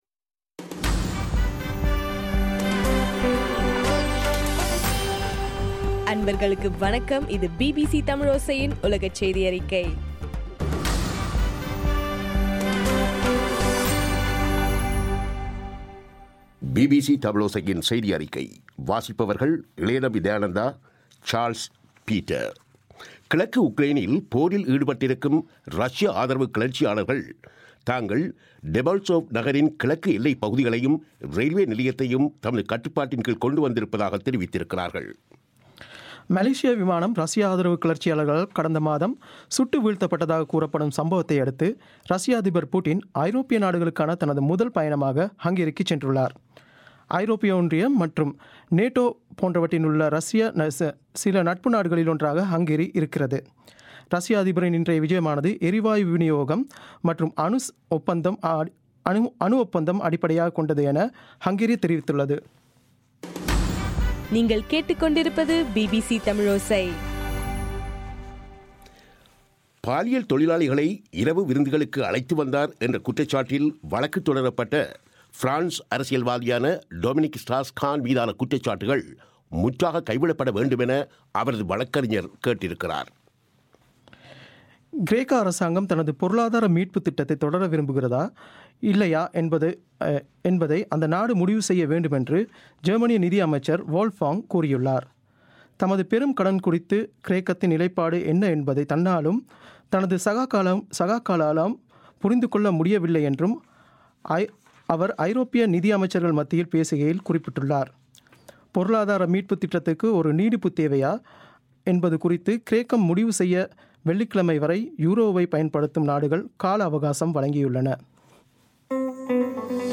இன்றைய ( பிப்ரவரி 17) பிபிசி தமிழோசை செய்தியறிக்கை